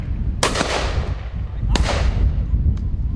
野鸡射击包1 " 几次射击都是在大风天气下进行的
描述：在雉鸡拍摄期间，在一个深谷中风很大的条件下，从一个上下射手拍摄了几张照片。在SONY FS7上使用RODE NTG1霰弹枪录制，音频直接从.mxf文件中提取并渲染为48kHz WAV文件。
Tag: 野鸡 射击 并排侧 过度和下 季节 射击 猎枪 射击 多风 一声枪响 拍摄